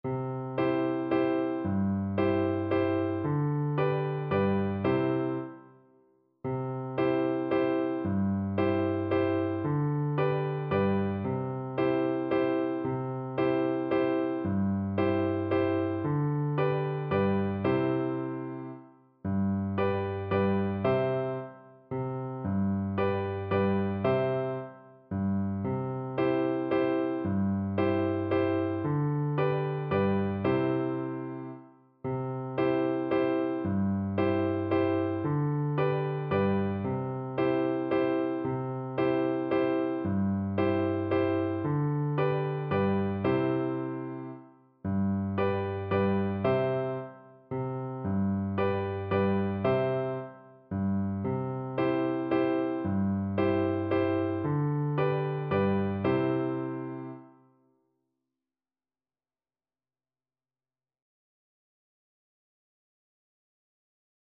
Steady one in a bar .=c.50
3/4 (View more 3/4 Music)
G5-A6
Traditional (View more Traditional Flute Music)